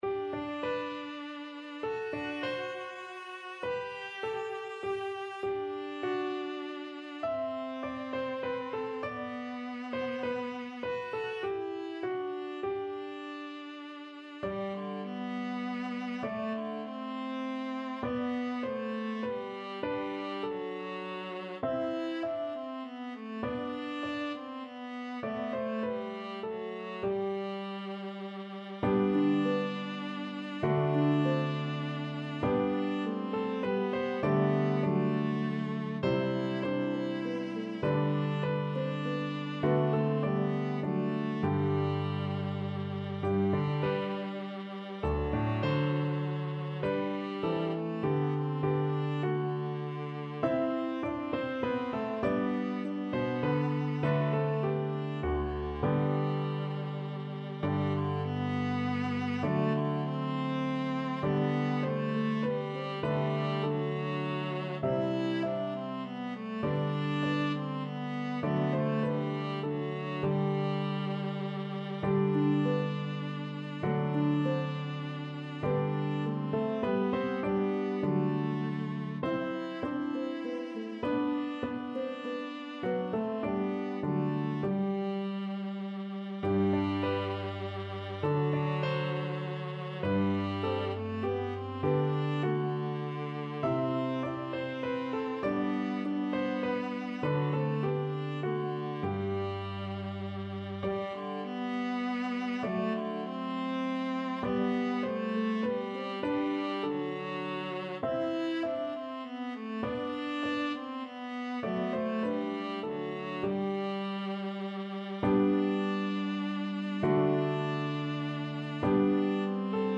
traditional round
Harp, Piano, and Viola version